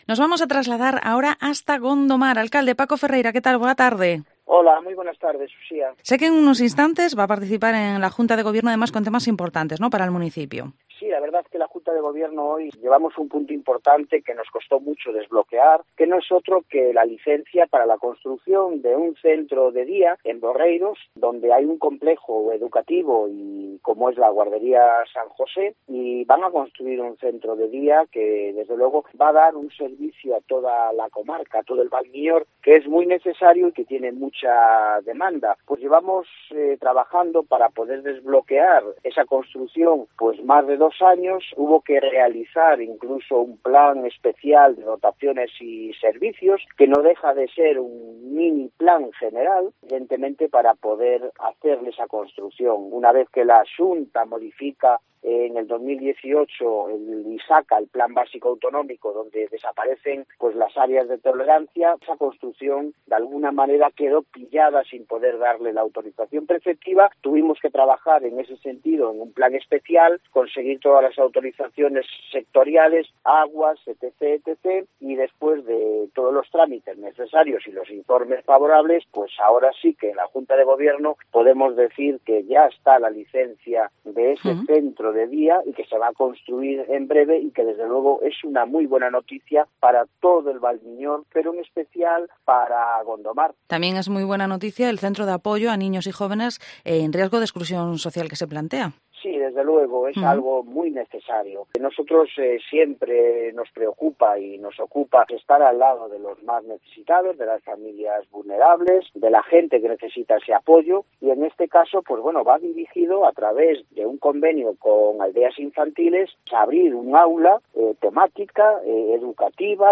Entrevista Alcalde de Gondomar, Paco Ferreira